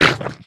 Minecraft Version Minecraft Version latest Latest Release | Latest Snapshot latest / assets / minecraft / sounds / entity / squid / hurt1.ogg Compare With Compare With Latest Release | Latest Snapshot
hurt1.ogg